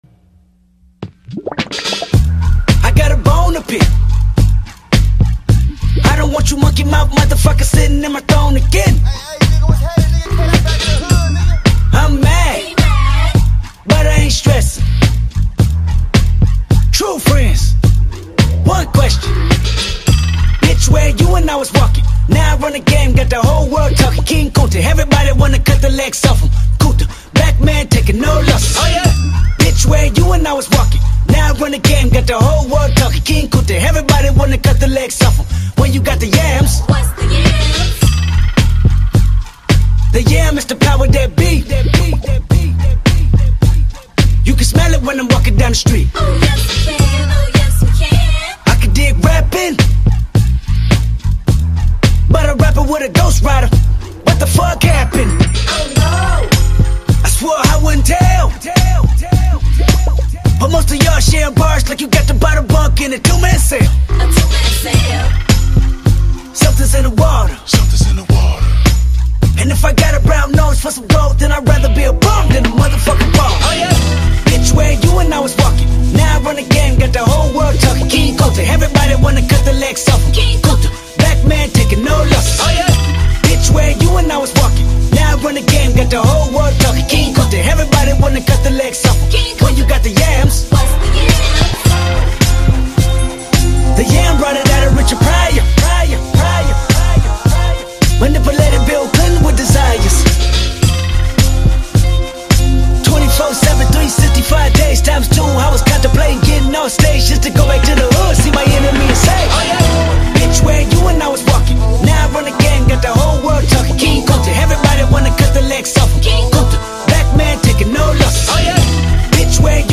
Hip‑Hop, Jazz‑Funk, Conscious Rap